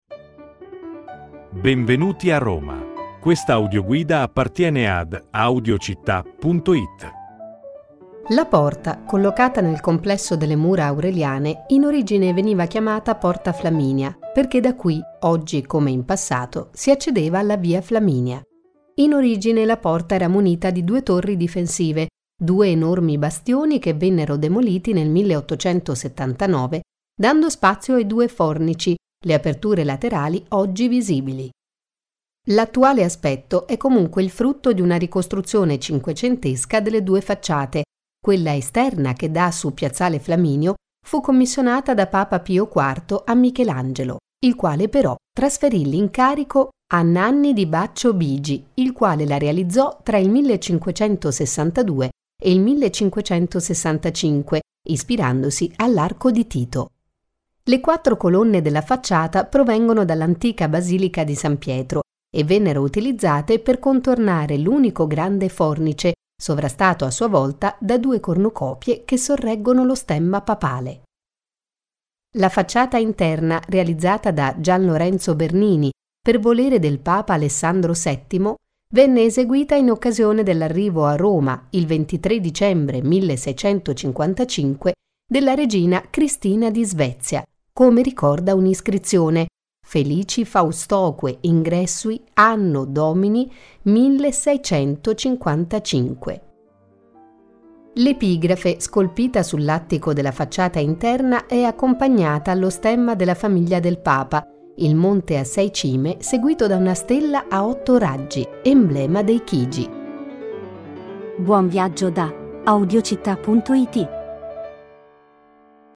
Audioguida Roma – Porta del Popolo